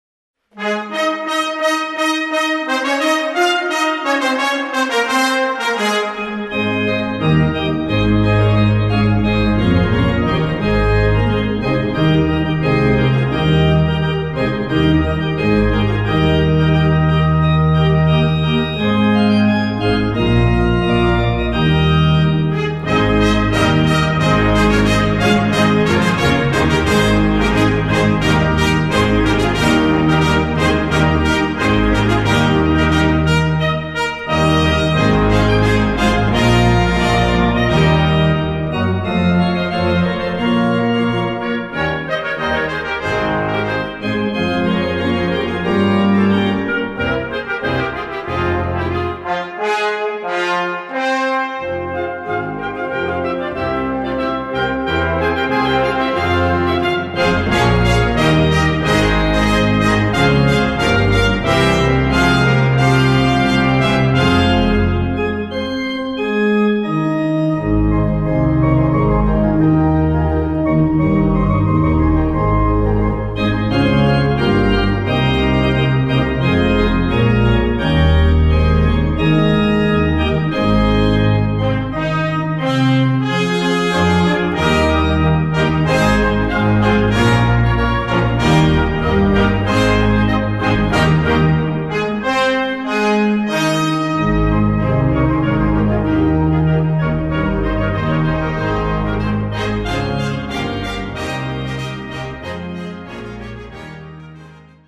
Gattung: Solo für Orgel und Blasorchester
Besetzung: Blasorchester